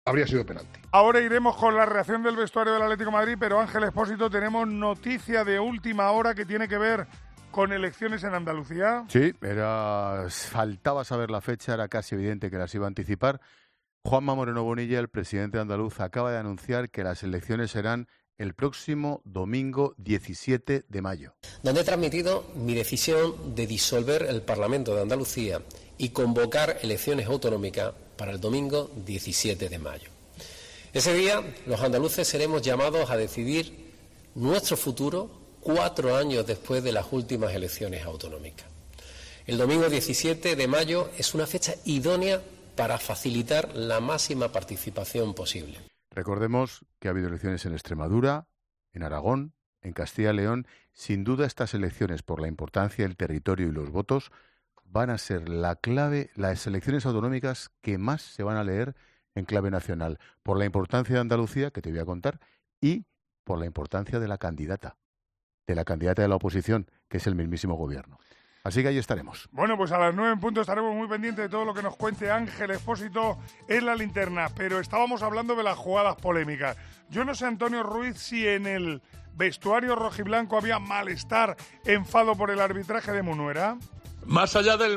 Expósito cuenta la última hora de la convocatoria de elecciones en Andalucía
La noticia, que supone un leve 'adelanto técnico' de varias semanas, ha sido comentada en el programa 'La Linterna' de COPE, donde el periodista Ángel Expósito ha analizado la última hora de la convocatoria.